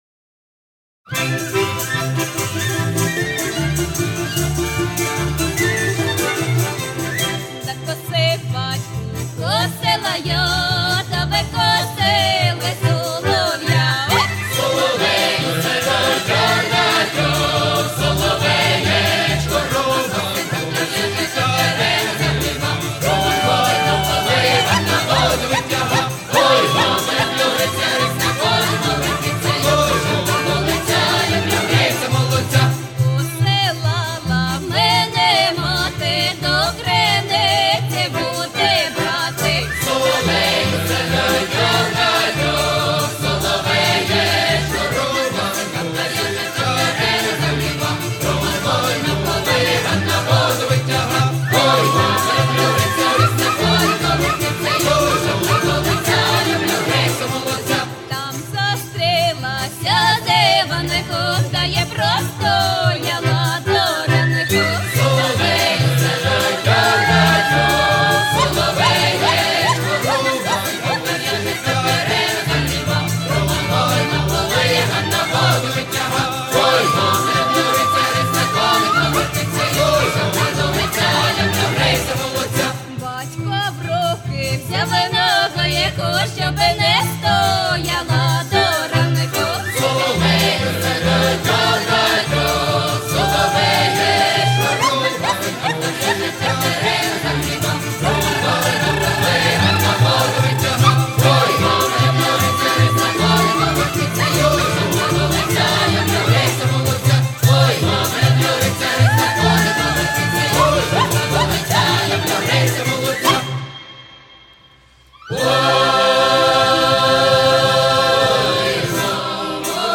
Всі мінусовки жанру Традиційні UA
Плюсовий запис